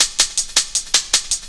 Shaker Loop C 160.wav